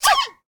latest / assets / minecraft / sounds / mob / panda / sneeze1.ogg
sneeze1.ogg